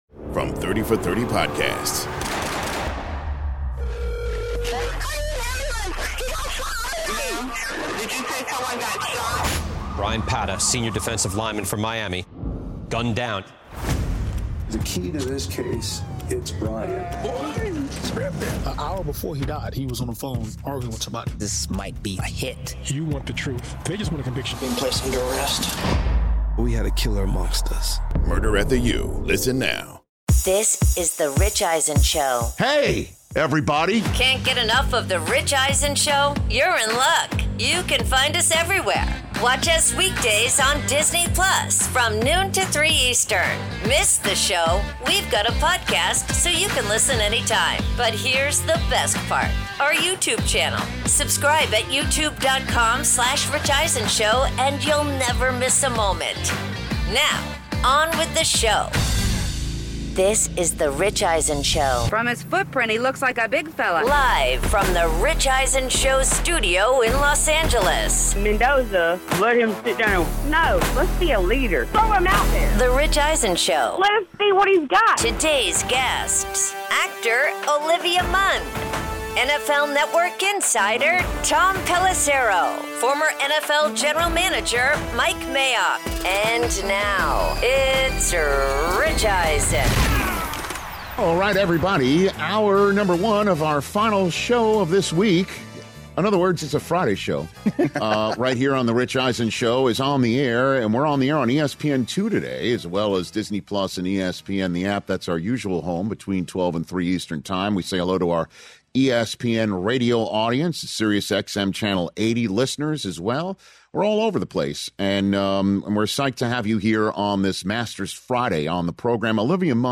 Hour 1: The Masters Day 1 Reactions, plus Actress Olivia Munn In-Studio